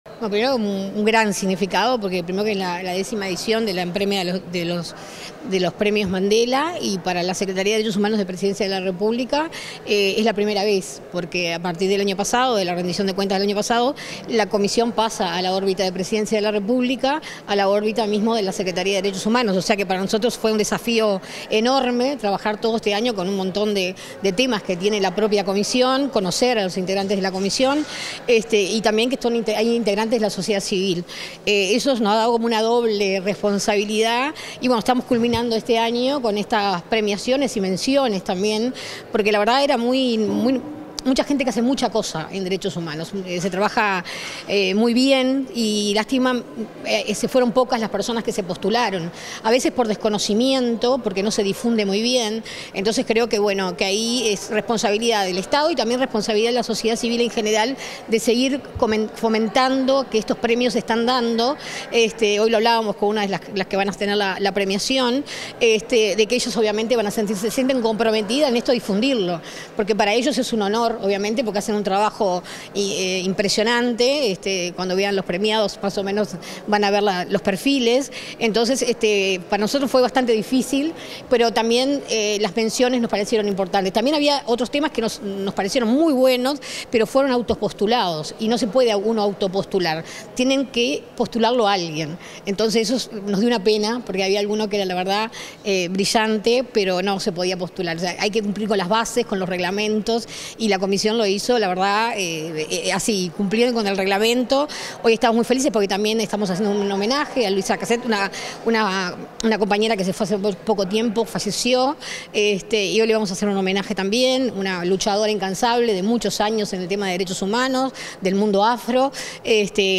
Declaraciones a la prensa de la presidenta de la Comisión de Derechos Humanos de la Presidencia de la República
Declaraciones a la prensa de la presidenta de la Comisión de Derechos Humanos de la Presidencia de la República 12/12/2024 Compartir Facebook X Copiar enlace WhatsApp LinkedIn Tras la ceremonia de entrega de los Premios Nelson Mandela, la presidenta de la Comisión de Derechos Humanos de la Presidencia de la República, Sandra Etcheverry, dialogó con la prensa.